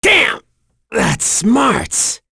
Mitra-Vox_Dead_b.wav